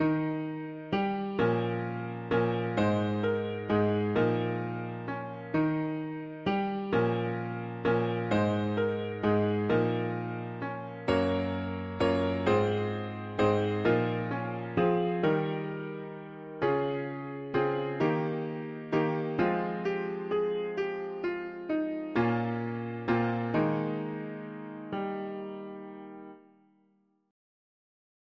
Key: E-flat major
Tags english secular 5part